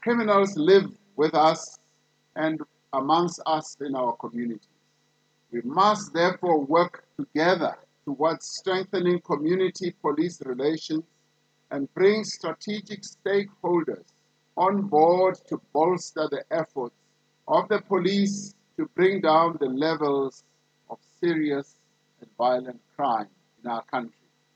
During the annual South African Police Service Commemoration Day, Ramaphosa said more would also be done to bring to book those who are responsible for killing police officers.